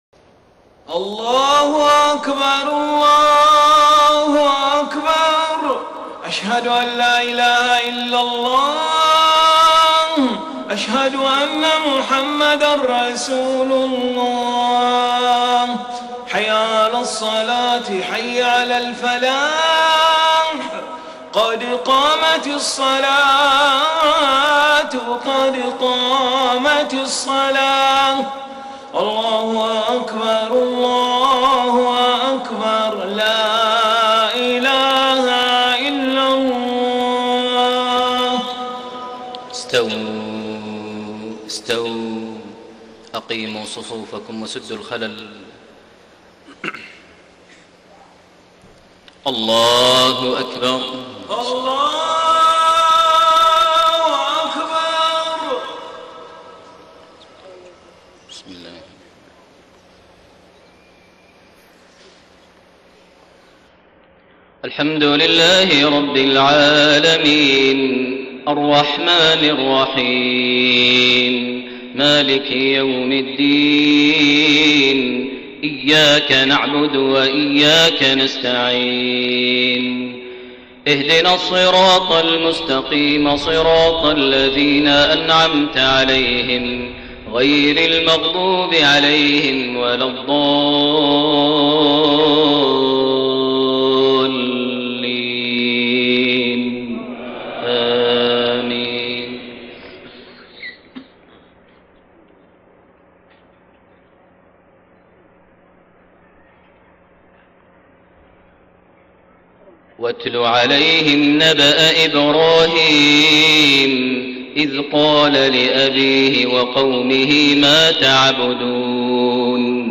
صلاة المغرب 23 جمادى الأولى 1433هـ من سورة الشعراء 69-104 > 1433 هـ > الفروض - تلاوات ماهر المعيقلي